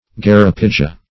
Search Result for " geropigia" : The Collaborative International Dictionary of English v.0.48: Geropigia \Ger`o*pig"i*a\, n. [Pg. geropiga.] A mixture composed of unfermented grape juice, brandy, sugar, etc., for adulteration of wines.